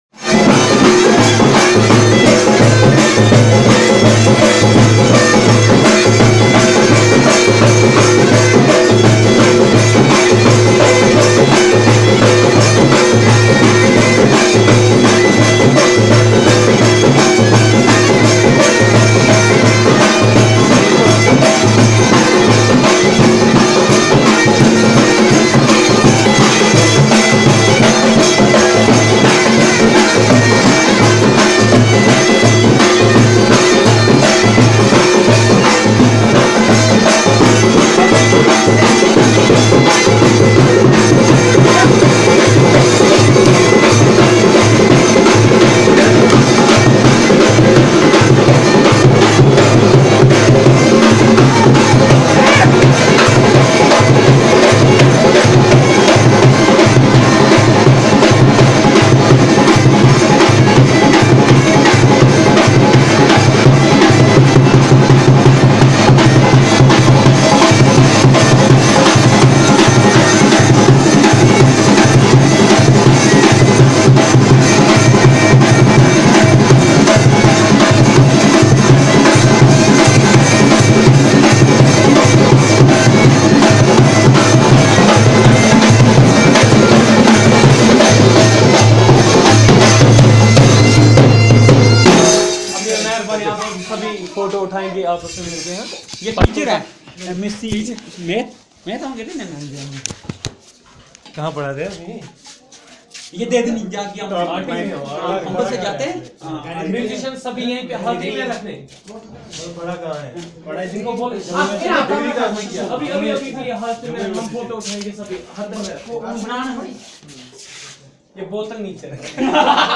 Narrative about the musical instruments used in weddings
NotesThis is a video (albeit black/No visual) of music in weddings (apparently).